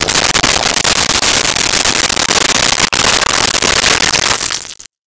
Liquid Pour Into Cup 03
Liquid Pour into Cup 03.wav